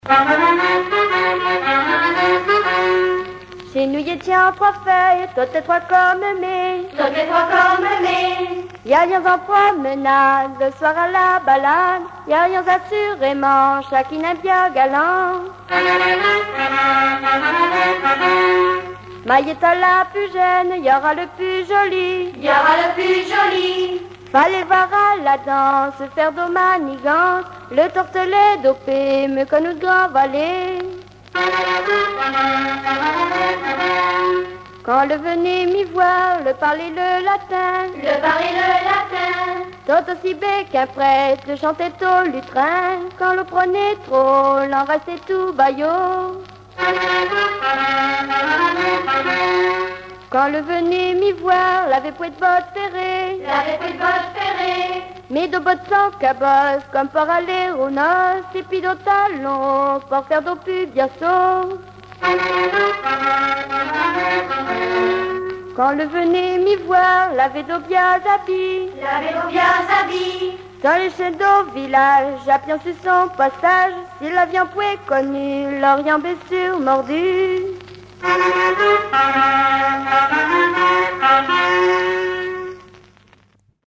Informateur(s) Les Joyeux vendéens association
Genre strophique
Pièce musicale inédite